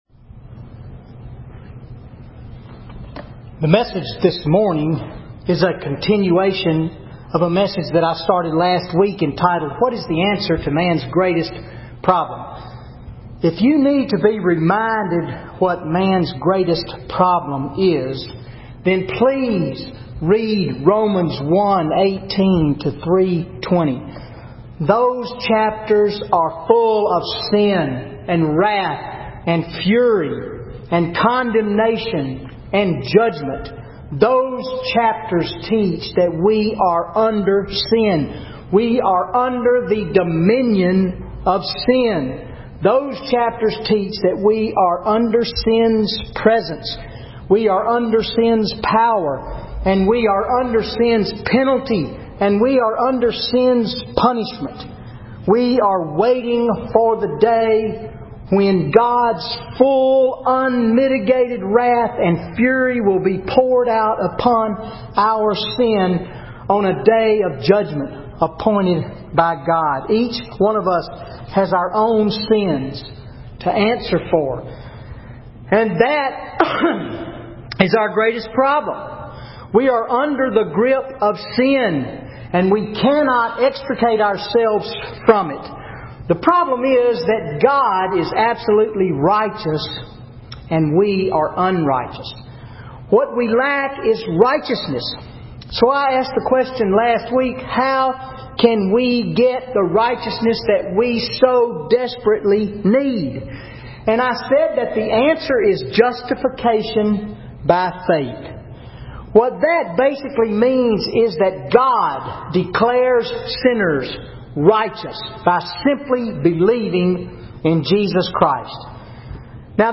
Sunday Sermon September 1, 2013 Romans 3:21-31 How Can God be Just and the Justifier of Sinners?